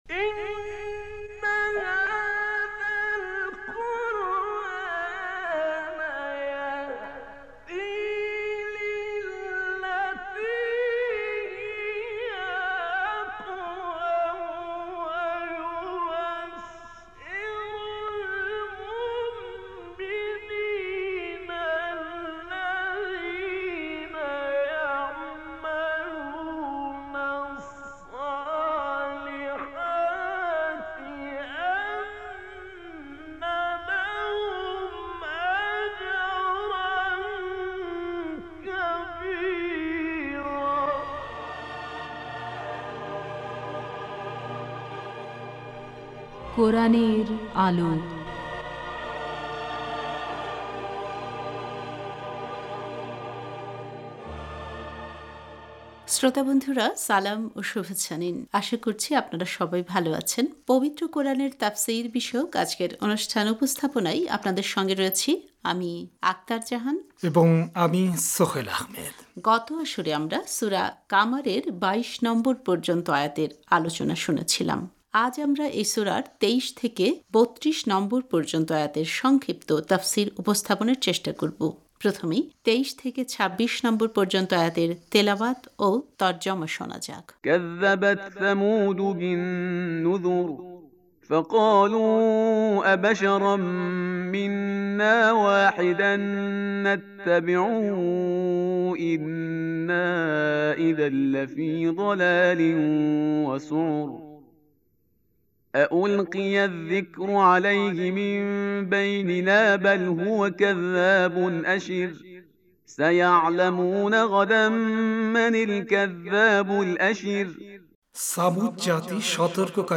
আজ আমরা এই সূরার ২৩ থেকে ৩২ নম্বর পর্যন্ত আয়াতের সংক্ষিপ্ত তাফসির উপস্থাপনের চেষ্টা করব। প্রথমেই ২৩ থেকে ২৬ নম্বর পর্যন্ত আয়াতের তেলাওয়াত ও তর্জমা শোনা যাক: